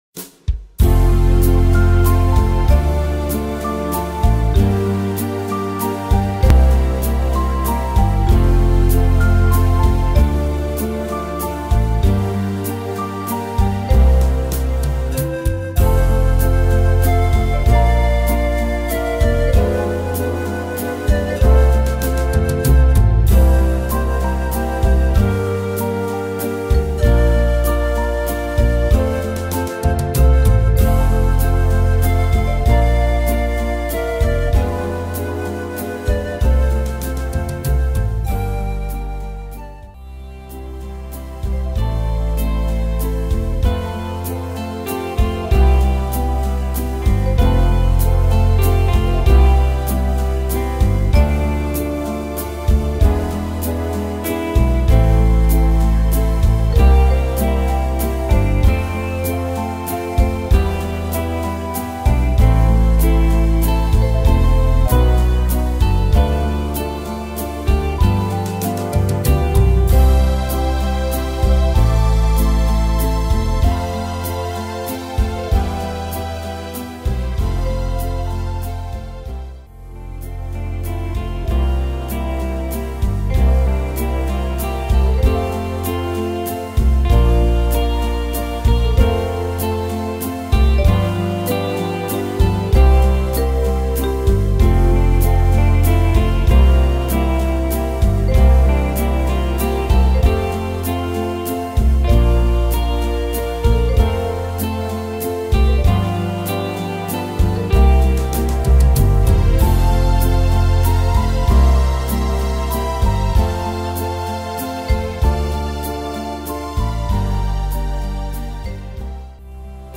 Tempo: 96 / Tonart: F-Dur